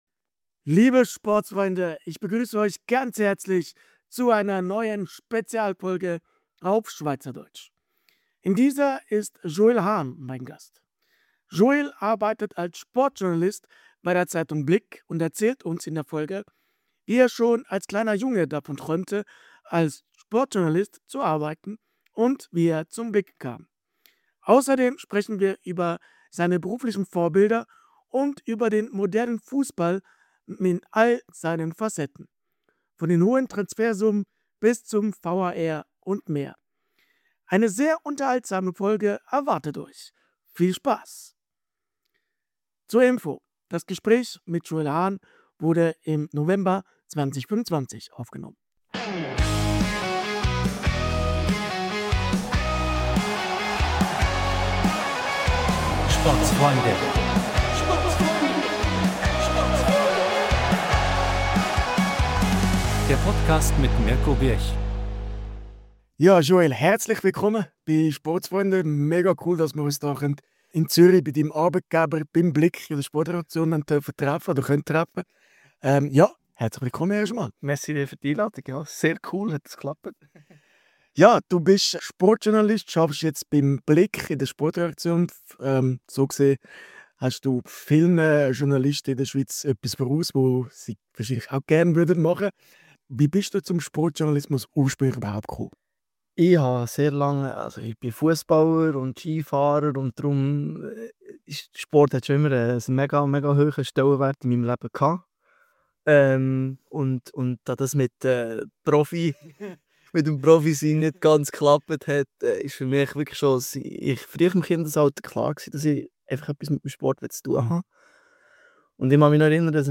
Wir starten mit einer neuen SPEZIALFOLGE auf SCHWEIZERDEUTSCH in die Woche!